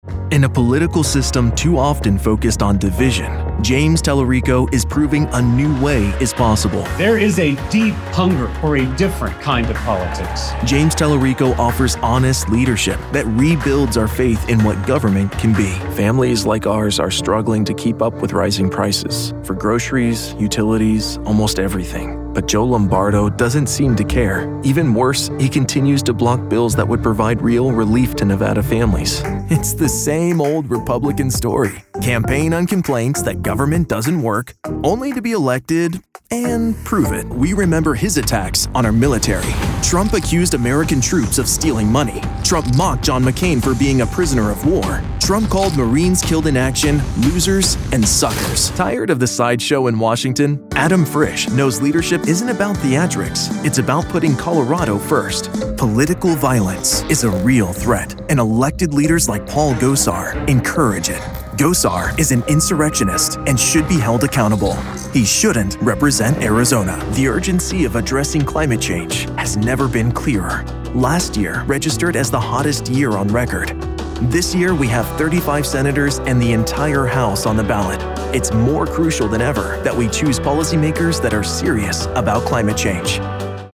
An upbeat voiceover artist for commercials, corporate narration, videos, video games, and more.
Political